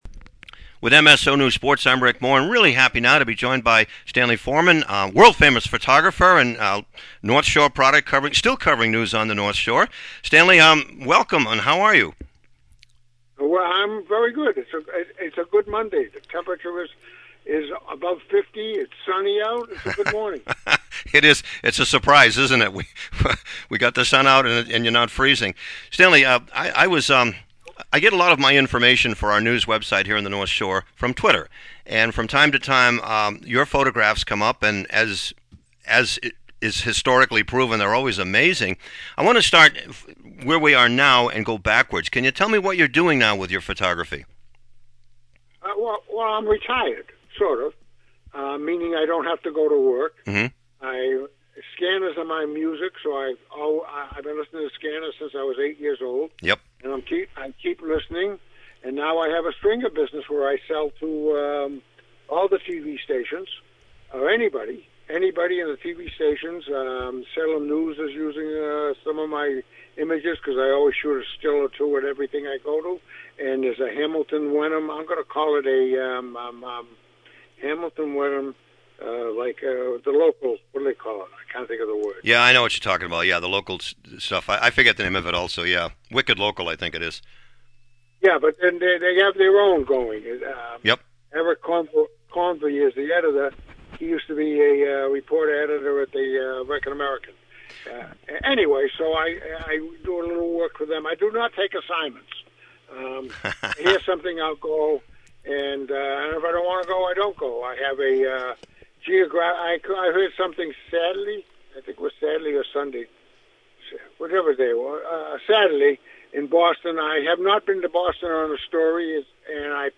In the interview below, he reflects on where he’s been and where he is still going.